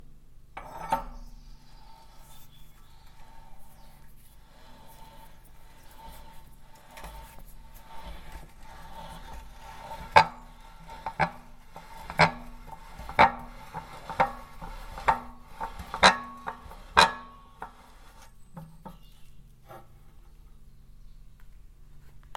Duration - 22s Environment - Large shed with corrugated roof, many different tools surrounds it. Description - This is a vice which is mounted onto a large solid table. It is made of steel and the table it is based on is wooden. The recording is of a user closing the vice. So at the beginning of the track you can hear the user picking the handle as it crackles and then turn the handle to the required fully closed position.